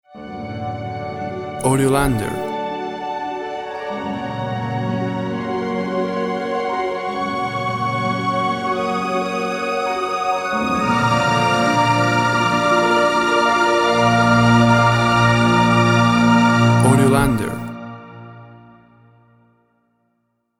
Harp and flutes rise to orchestral finish.
WAV Sample Rate 24-Bit Stereo, 44.1 kHz
Tempo (BPM) 80